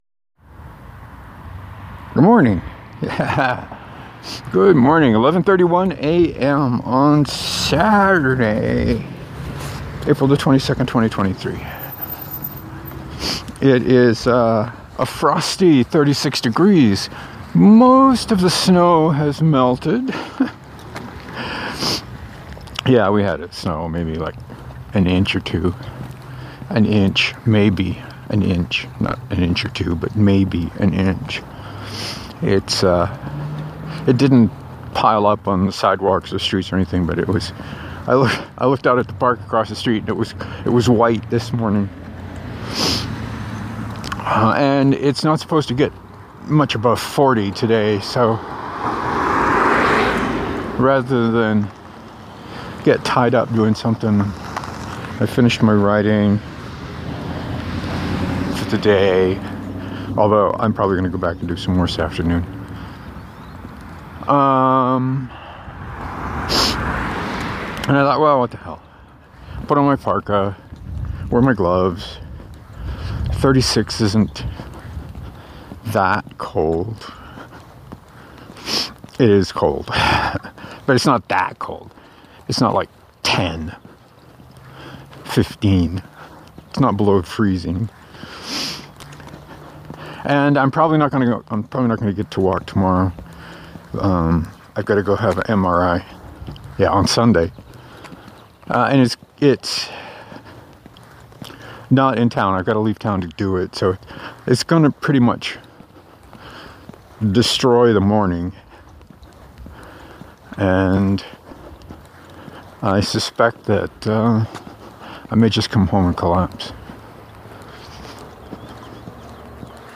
Most of the over night snow has melted but the mid-30F temps made the walk a bit sniffy. I talked a lot about bread and ARK Survival.